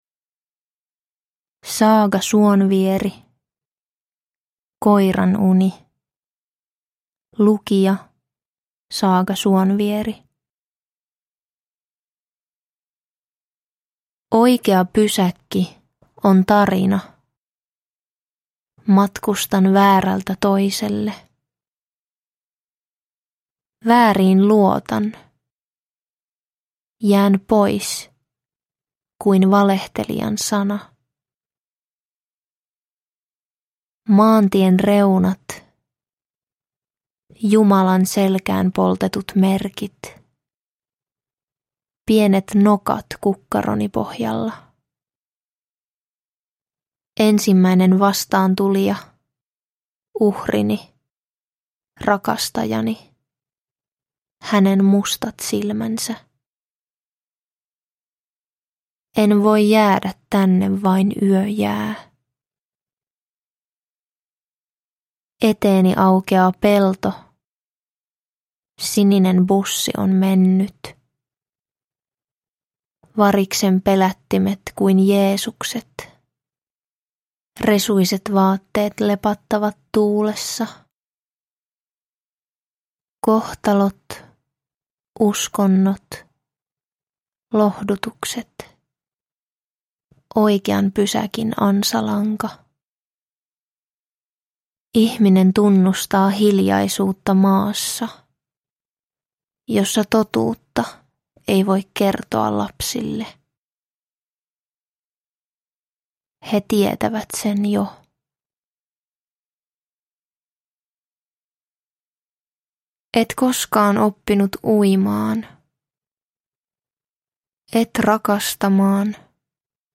Koiranuni – Ljudbok – Laddas ner